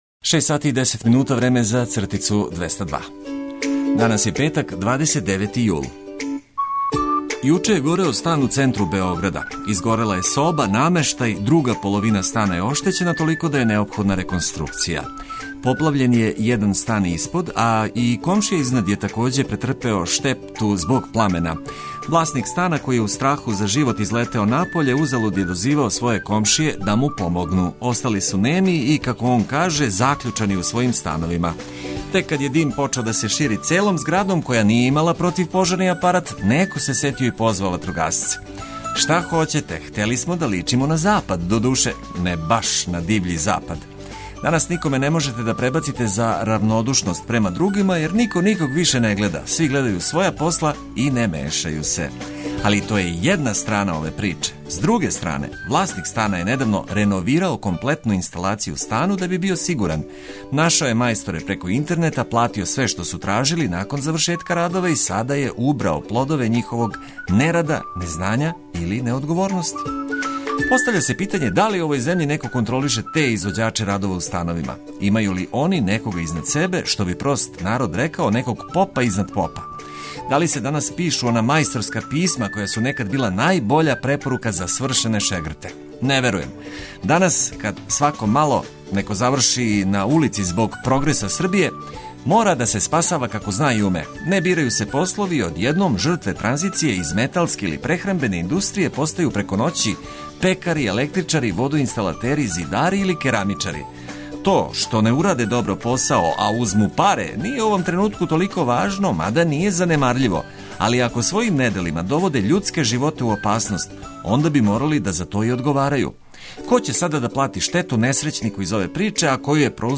Пробудићемо се и овог летњег јутра уз много блиставих и ведрих нота са свих страна света.